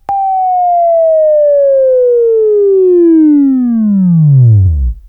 Buzz